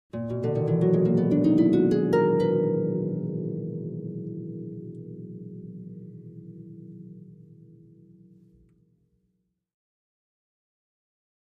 Harp, Ascending Gliss, 7th Chords, Type 3